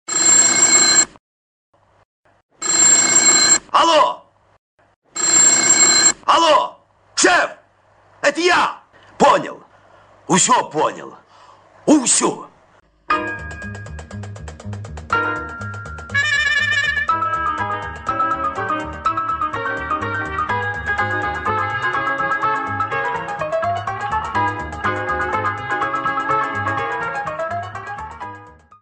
• Качество: 224, Stereo
смешные
из советского фильма